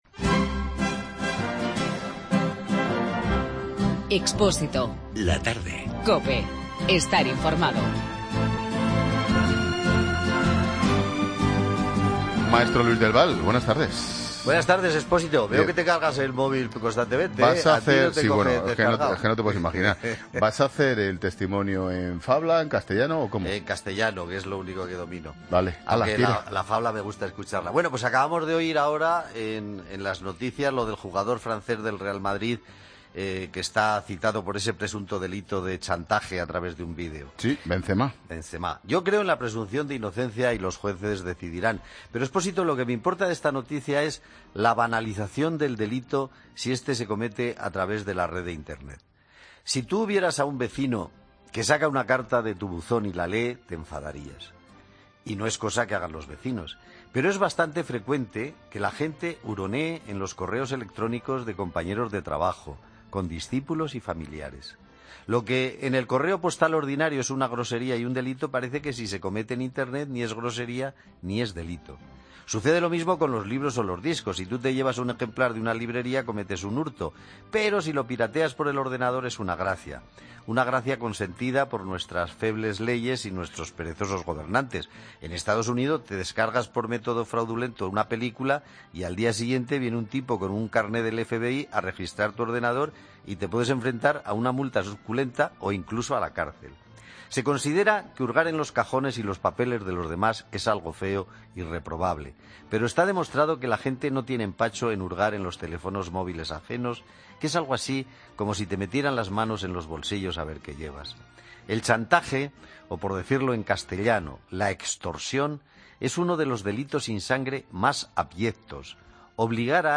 Luis del Val hace un comentario sobre los delitos cometidos en el entorno digital (extorsión, piratería y violación de la intimidad) por parte de los menores en España.